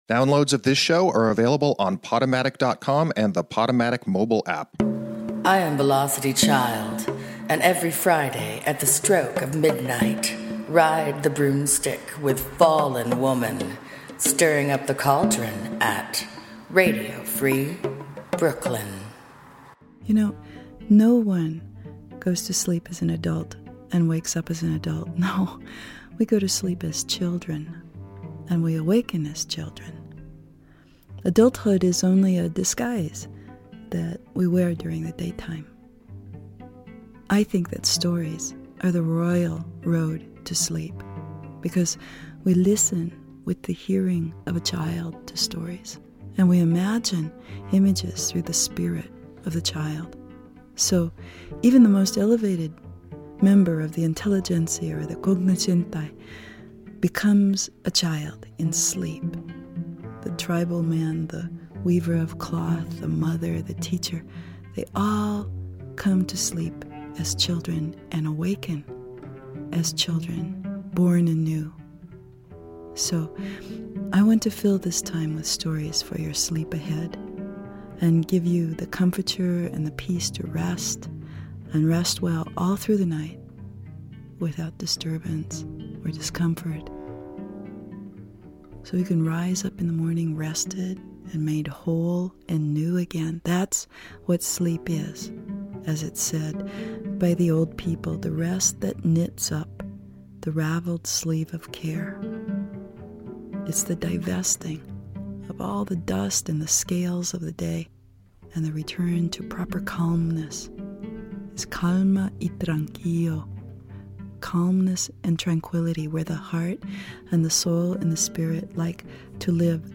Facebook Twitter Headliner Embed Embed Code See more options Featuring bedtime stories told by Clarissa Pinkola Estés. Find yourself a comfortable position, lay back, close your eyes, snuggle up next to your inner child and take a story book trip with us!